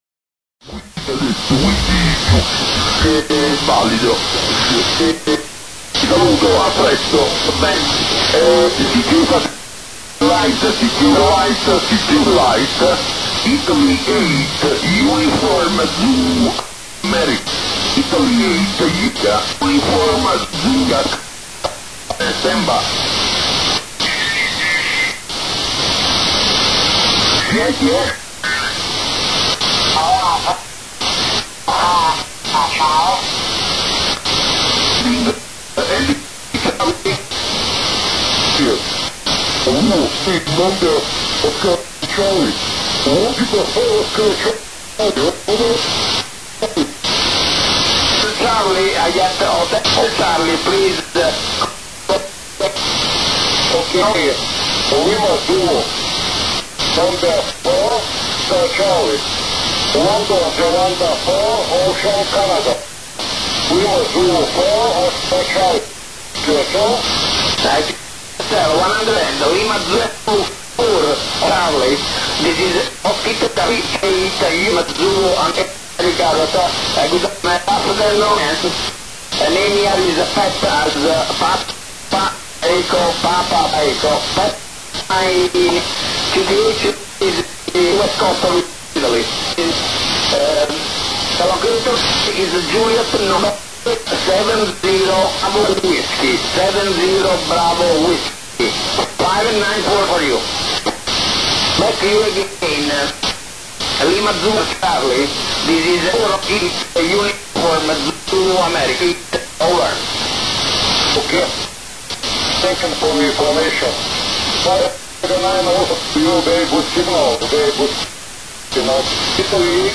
Qatar が AMSAT-DL などの協力を得て打ち上げた、アマチュア初の静止衛星 QO -100 が一般公開をはじめたようです。 アフリカ上空の静止衛星ですので、ヨーロッパ、中東などしかカバーせず、日本から利用することはできませんが、 こちらの Web-SDR で、交信内容をキャッチすることができます。
当局のインターネット回線が遅いこともあり、ちょっととぎれとぎれになりますが、結構良く聞こえてますねえ。